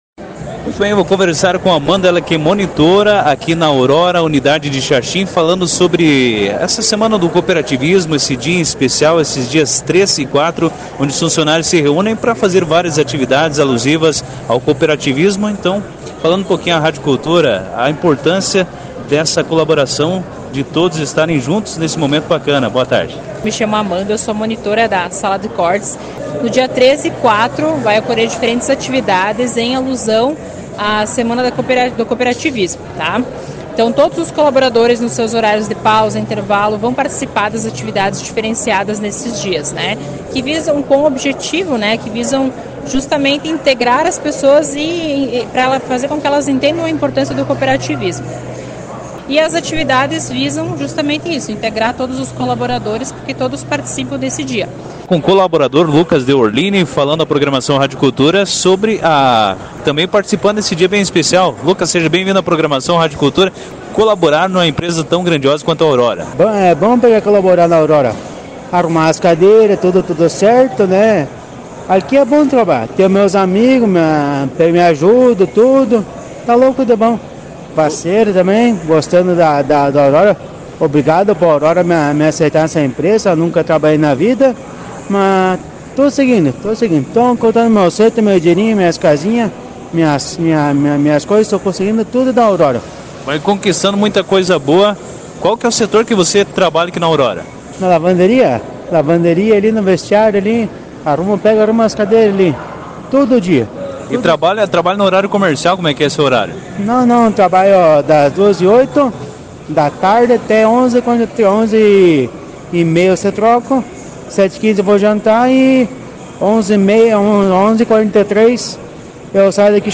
conversou com colaboradores